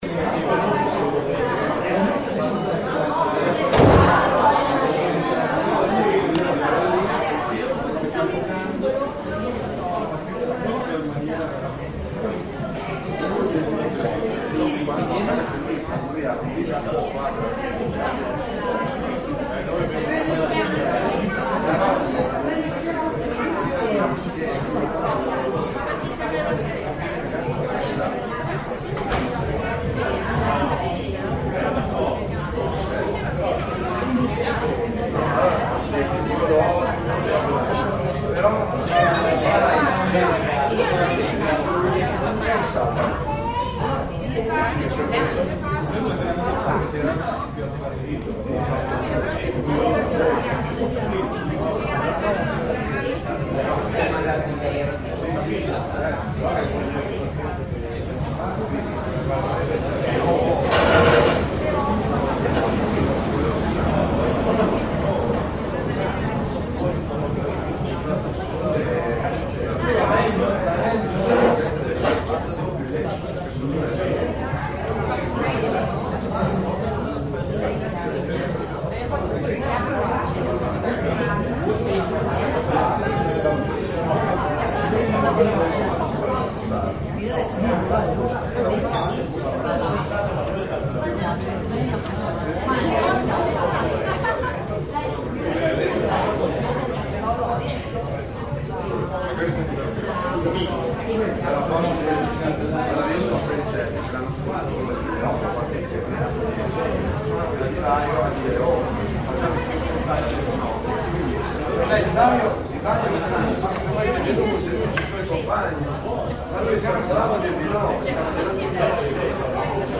就在RaccattaRAEE实验室外面，有一个酒吧，里面有很多人在玩耍。
Tag: 喋喋不休 音景 交通 噪音 博洛尼亚 现场录音 户外